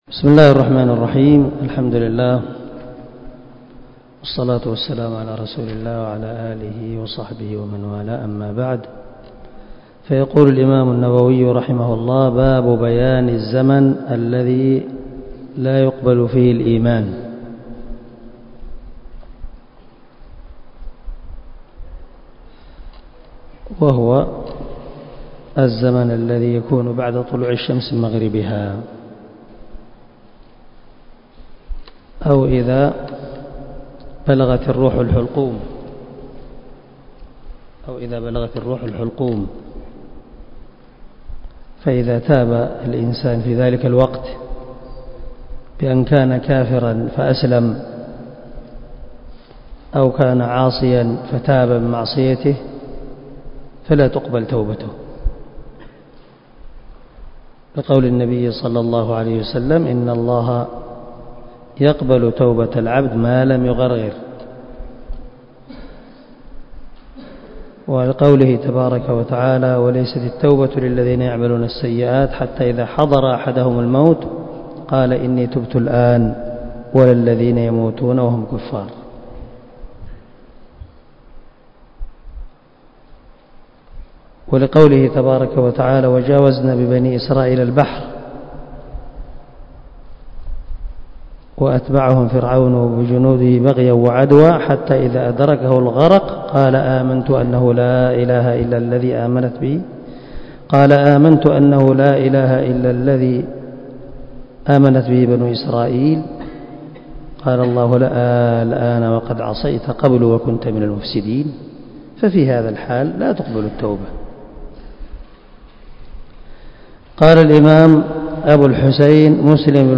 117الدرس 116 من شرح كتاب الإيمان حديث رقم ( 157 - 158 ) من صحيح مسلم